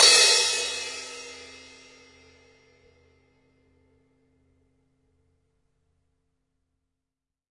描述：塔玛鼓套装打击乐撞击
标签： 打击乐器 崩溃 试剂盒 多摩 命中
声道立体声